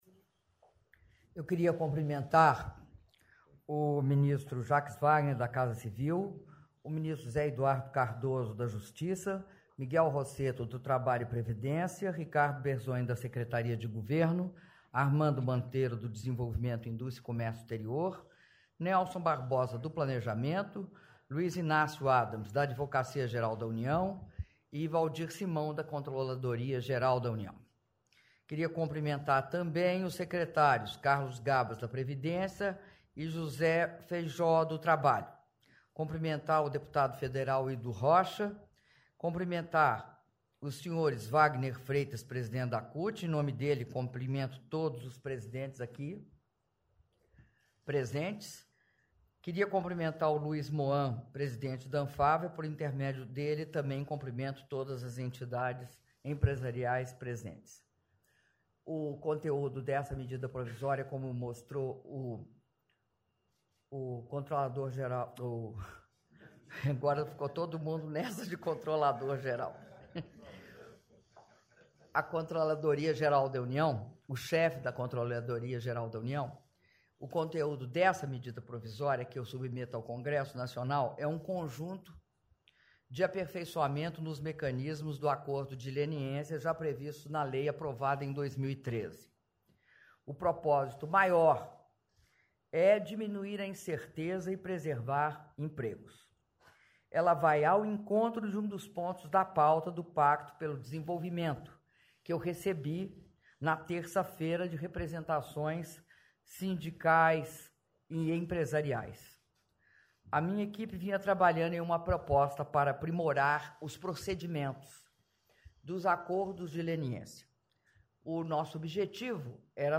Áudio do discurso da Presidenta da República, Dilma Rousseff, durante assinatura da Medida Provisória do Acordo de Leniência - Palácio do Planalto (07min30s)